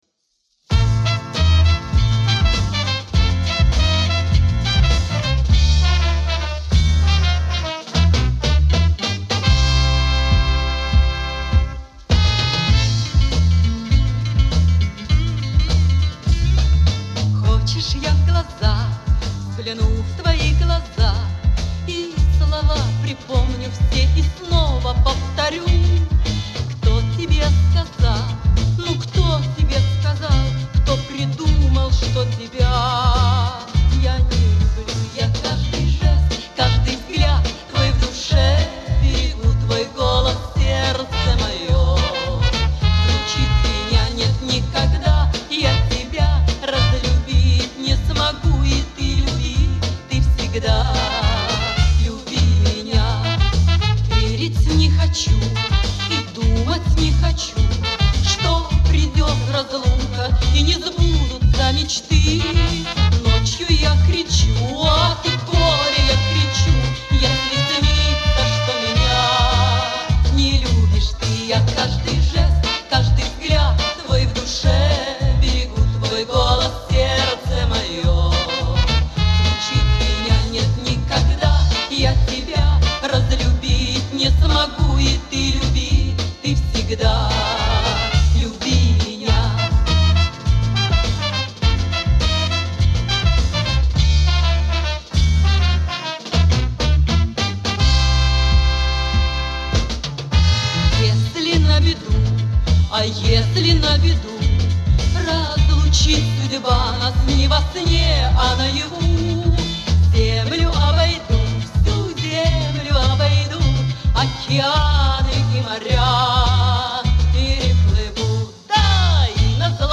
Правда, качество не очень.